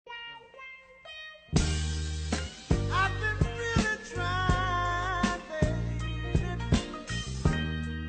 ROMANTIC SOUND EFFECT
Romantic sound effect is free to use
ROMANTIC_SOUND_EFFECT.mp3